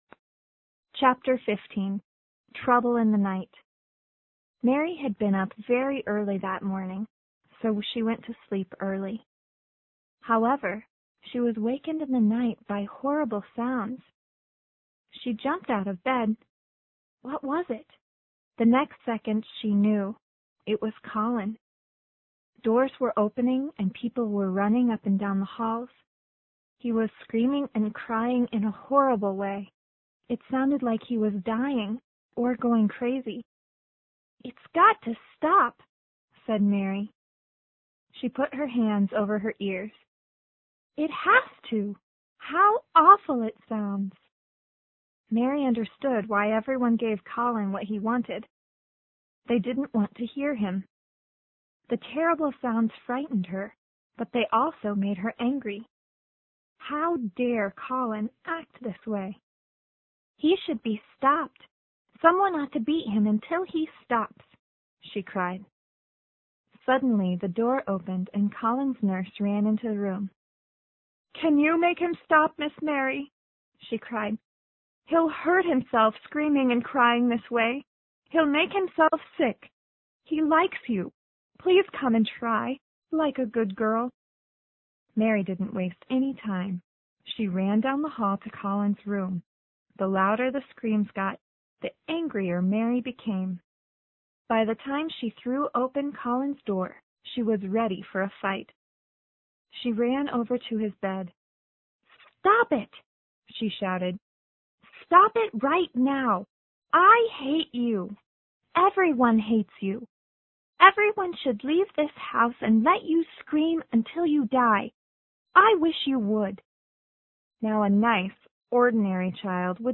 有声名著之秘密花园 Chapter15 听力文件下载—在线英语听力室